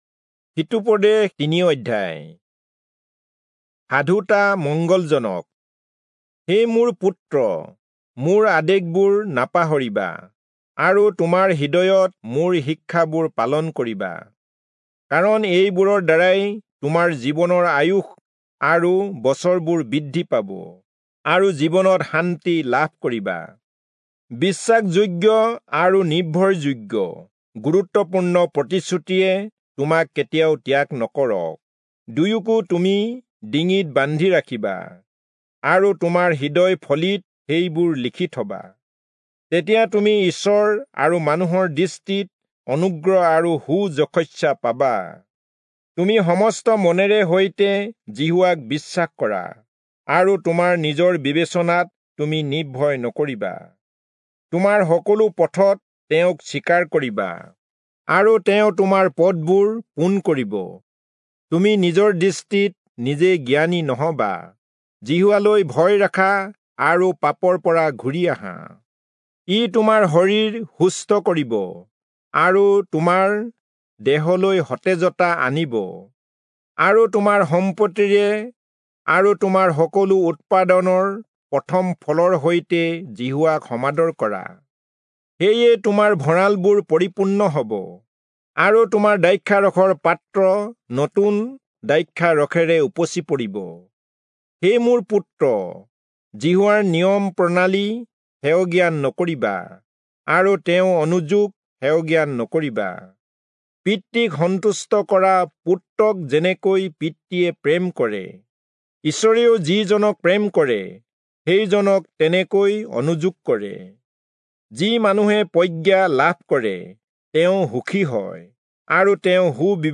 Assamese Audio Bible - Proverbs 15 in Kjv bible version